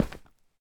stone1.ogg